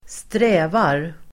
Uttal: [²str'ä:var]